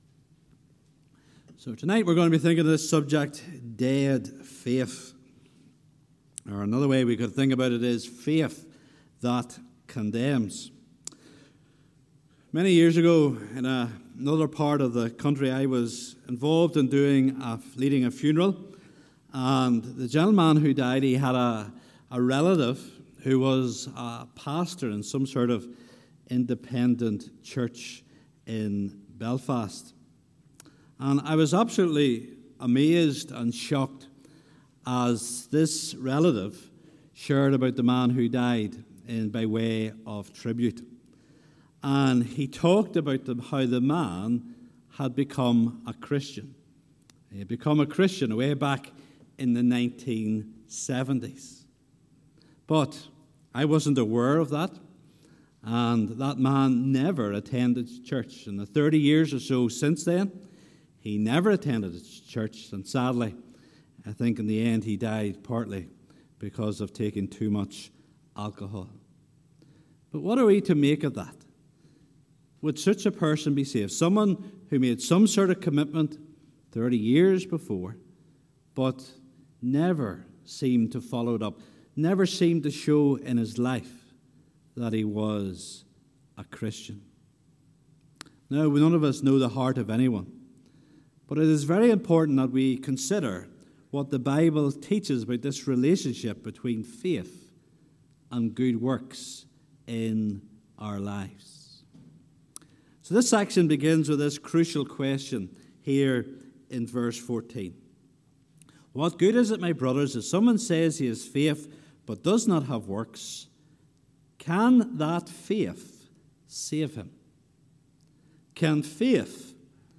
Listen to sermon audio. James 2:14–26 Faith Without Works Is Dead 14 What good is it, my brothers, if someone says he has faith but does not have works?